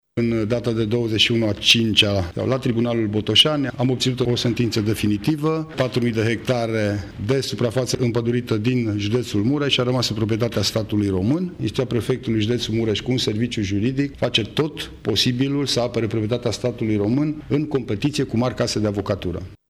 Prefectul de Mureș, Lucian Goga, a explicat că dosarul de retrocedare s-a aflat pe rolul instanțelor de aproape 10 ani: